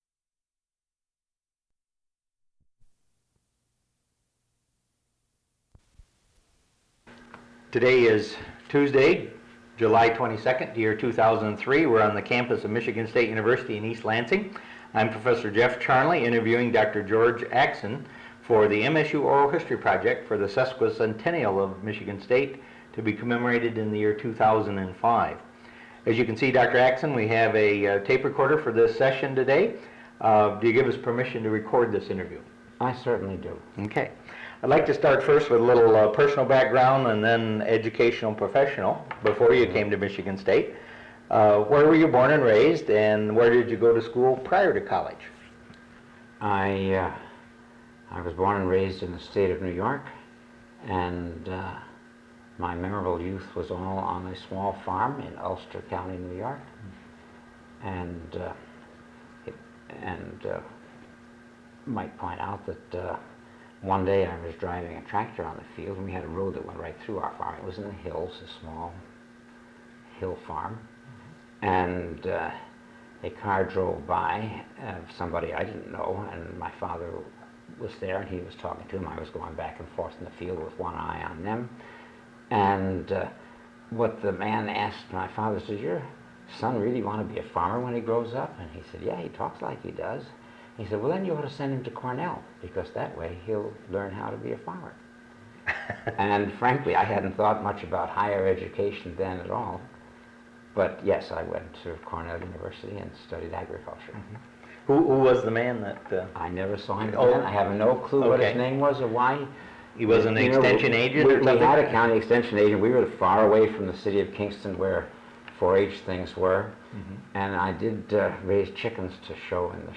Interview
Original Format: Audiocassettes